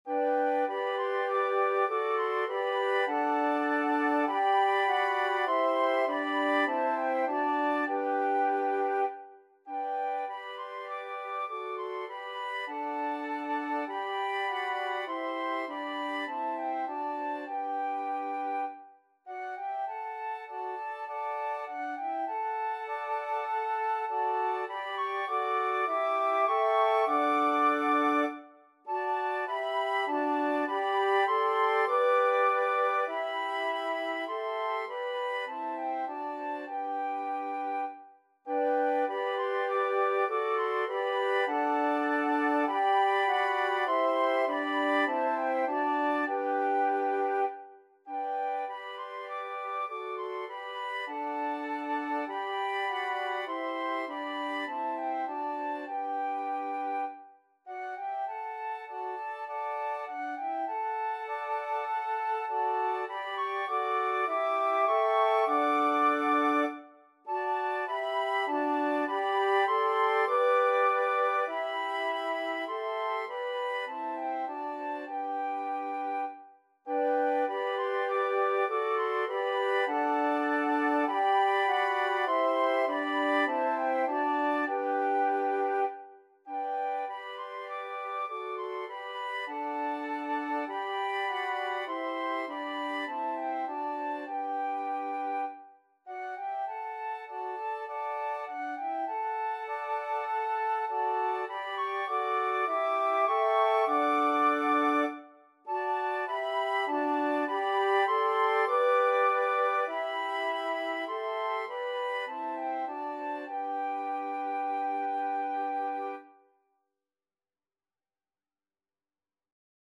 Christian church hymn
flute ensemble